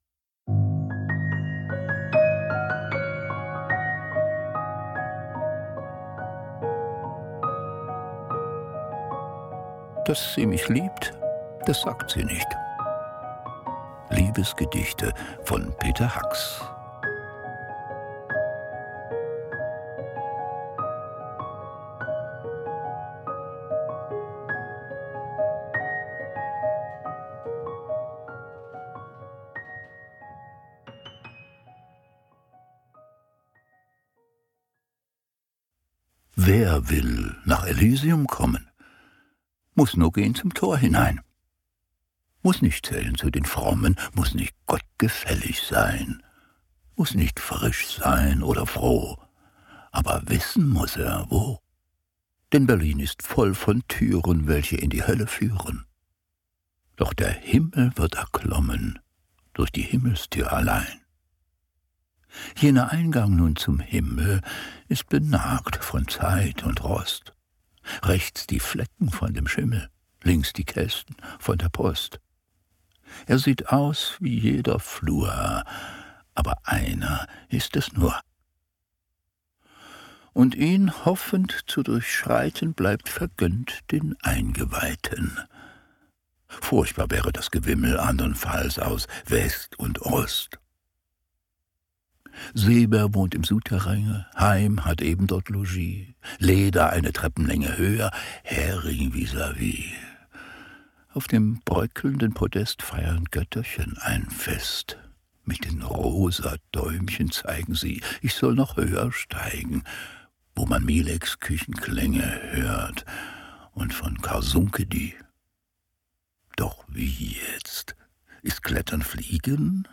Liebesgedichte von Peter Hacks - Gelesen und musikalisch begleitet von Christian Steyer
Die schönsten Liebesgedichte von Peter Hacks als Hörbuch
Schlagworte Christian Steyer • DDR Lyrik • Gedichte • Hörbuch • Hörbuch CD • Hörbuch Liebe • Liebesgedichte • Lyrik mit Musik • Peter Hacks • Peter Hacks Gedichte • Sinnlichkeit